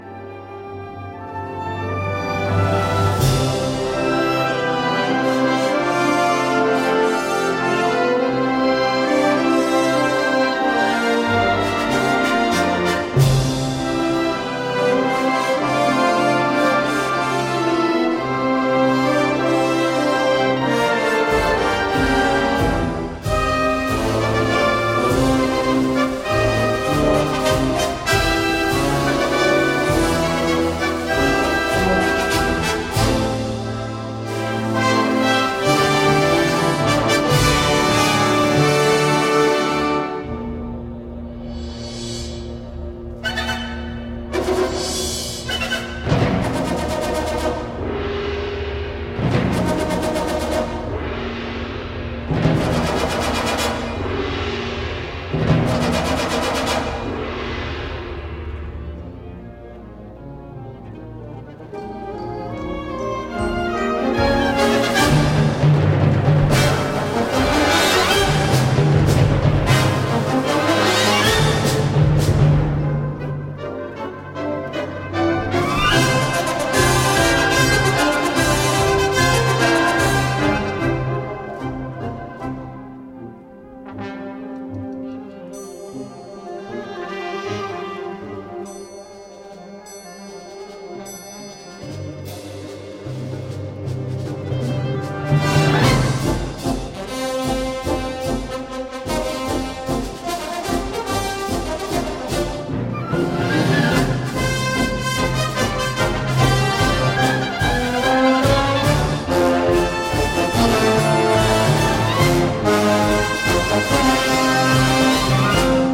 Gattung: Dokumentation in 4 Sätzen
Besetzung: Blasorchester